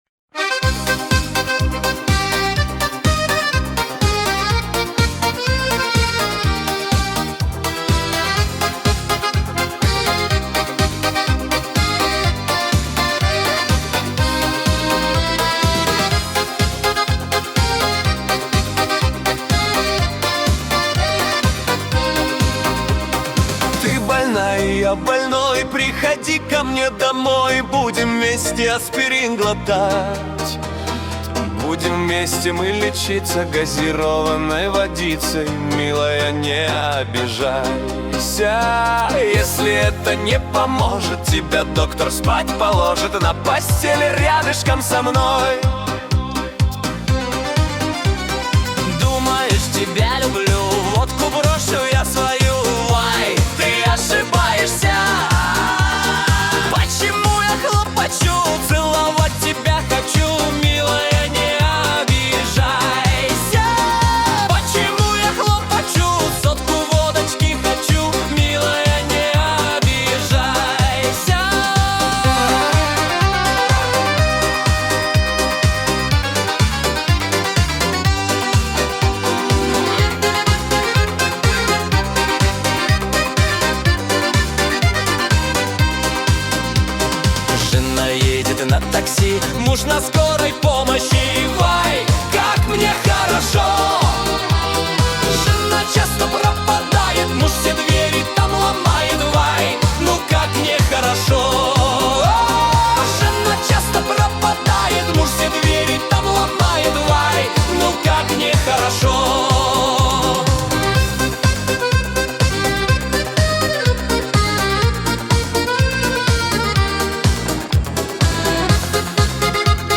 Качество: 320 kbps, stereo
Танцевальная музыка
Жанр трека танцевальный.